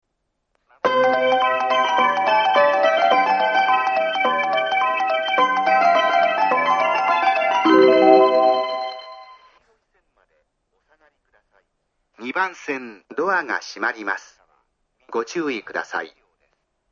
この駅は小さい駅ですし、高崎線という路線自体、鳴らす路線ではないので２コーラス目には滅多にはいりませんし、途中切りも多いです。
この駅の２番線を収録した際、上りホームの多くのスピーカーが壊れていましたので、収録の際はご注意ください。
２番線発車メロディー 曲は「Bellの響き」です。